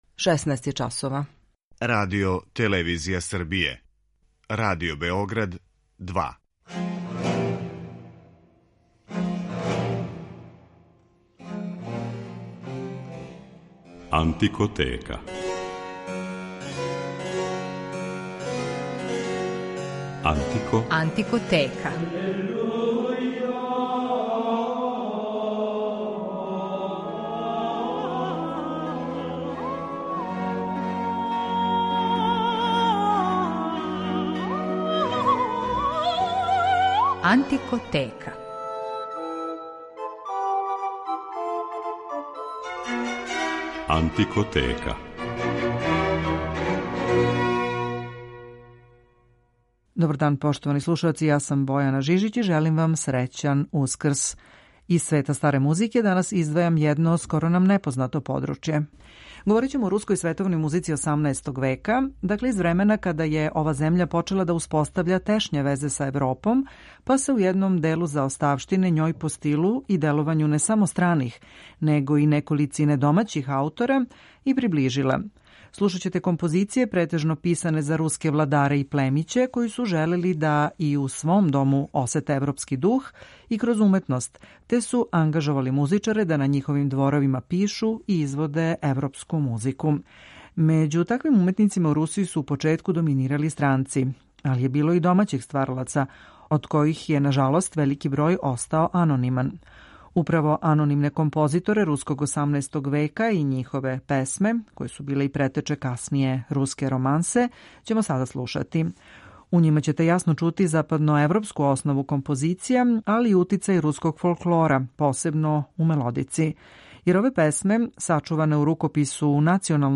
Говорићемо о руској световној музици из доба када је ова земља почела да успоставља блискије везе са Европом. Слушаћете композиције руског 18. века претежно писане за руске владаре и племиће који су ангажовали музичаре да на њиховим дворовима пишу и изводе европску музику. Била су то махом дела намењена забави, стварању атмосфере током балова и вечера.